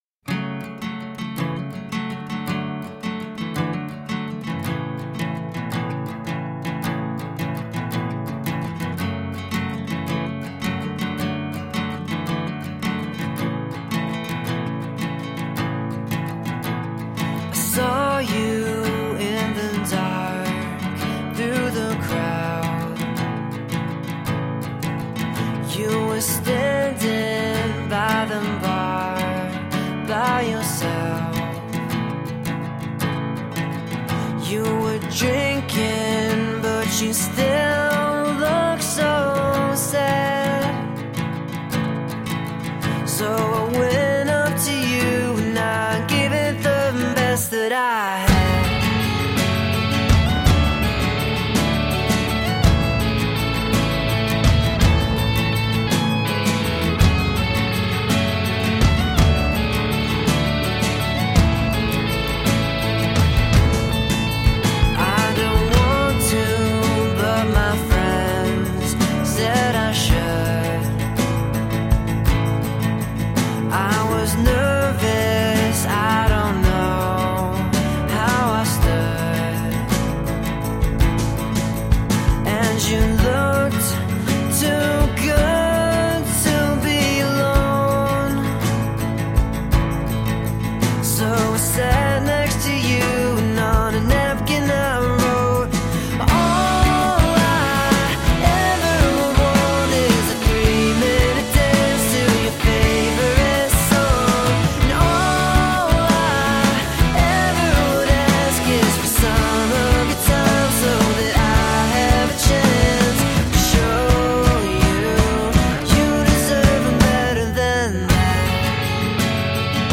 Guitar driven alternative rock.
Tagged as: Alt Rock, Rock, Folk-Rock, Indie Rock